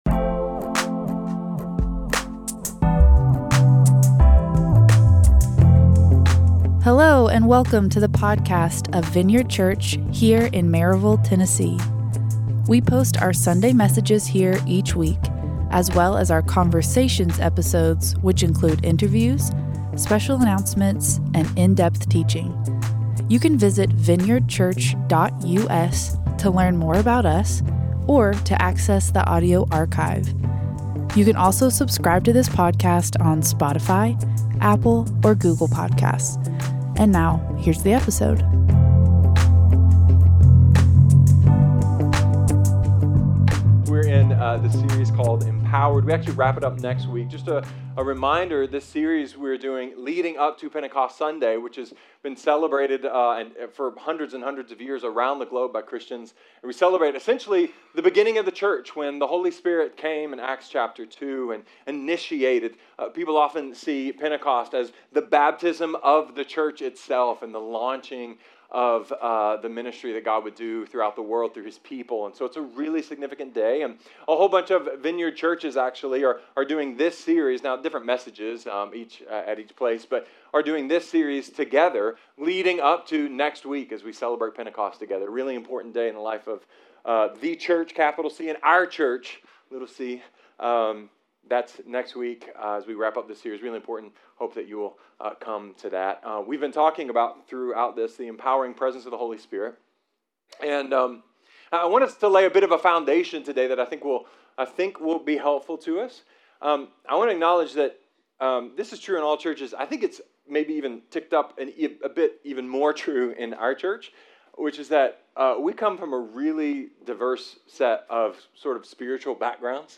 A sermon about soap dispensers, faith healer, and what’s different about the Vineyard’s understanding of the how the Holy Spirit empowers us today.